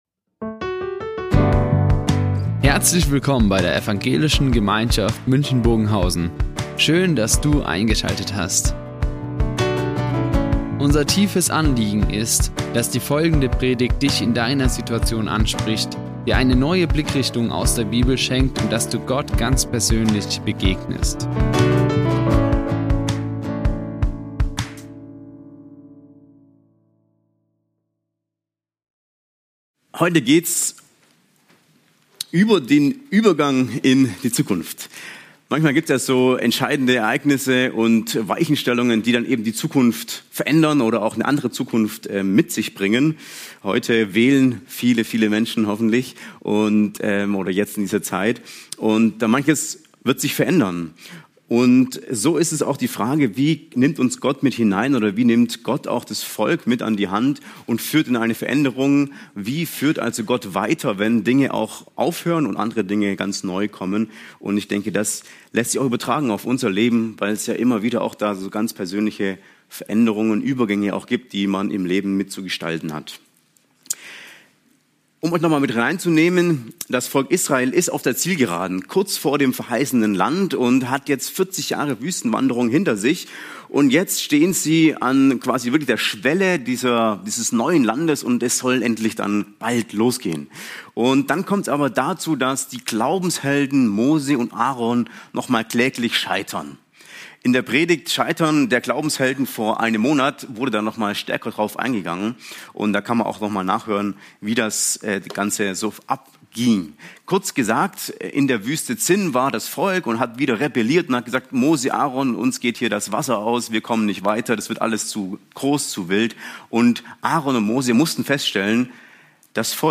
Übergang in die Zukunft | Predigt 4. Mose 27, 12-23 ~ Ev. Gemeinschaft München Predigten Podcast